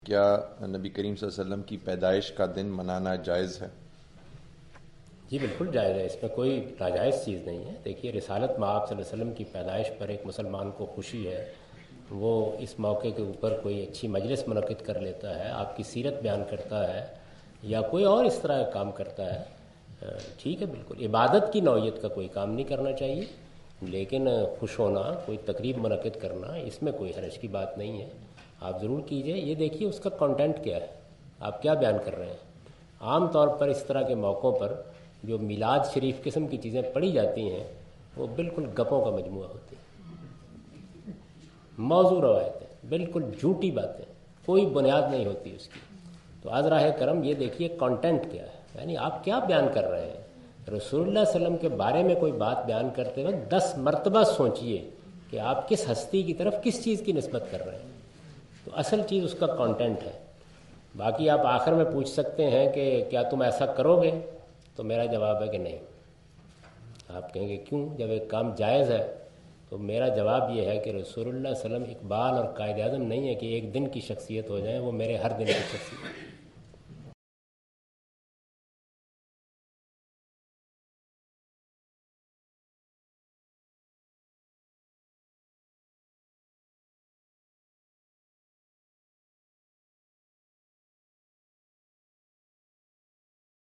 Javed Ahmad Ghamidi answer the question about "Celebrating Birthday of Prophet Muhammad (sws)" during his visit to Queen Mary University of London UK in March 13, 2016.
جاوید احمد صاحب غامدی اپنے دورہ برطانیہ 2016 کےدوران کوئین میری یونیورسٹی اف لندن میں "حضرت محمد الرسول اللہ ﷺ کا یوم پیدایش منانا" سے متعلق ایک سوال کا جواب دے رہے ہیں۔